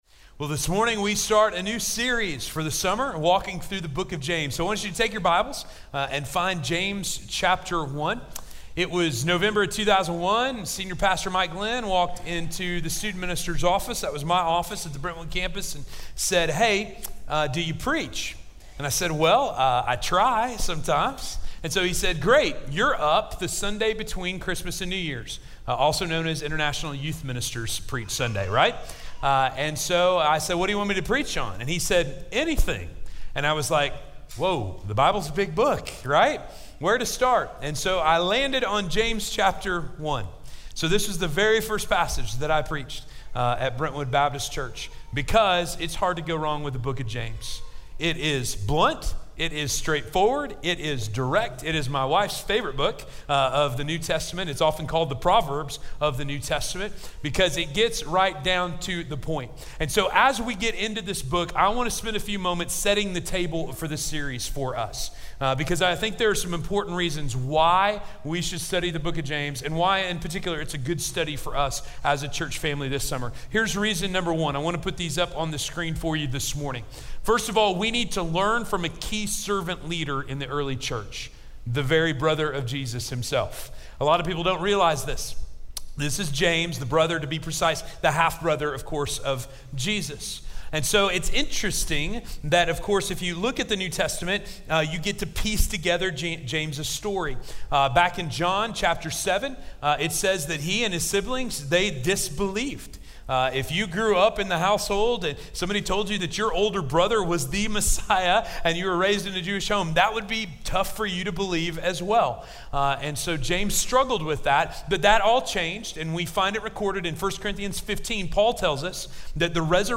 Faith Tested - Sermon - Station Hill